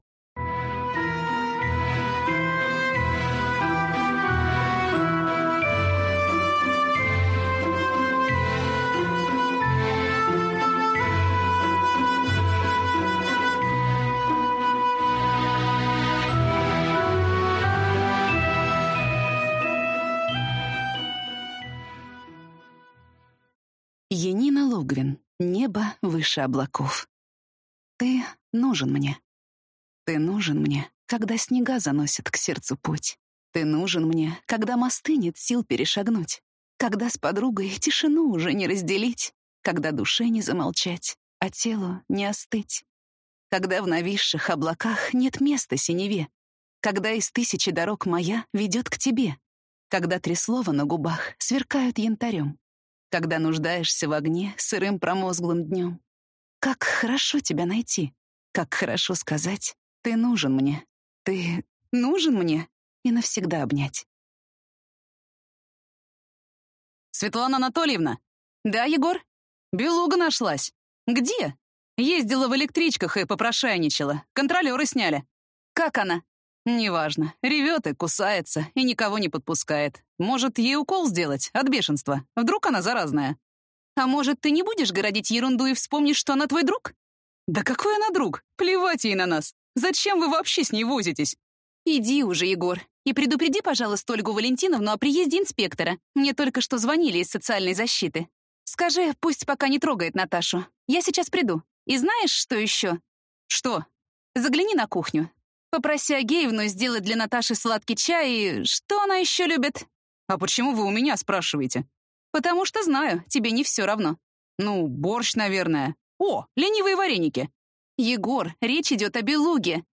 Аудиокнига Небо выше облаков | Библиотека аудиокниг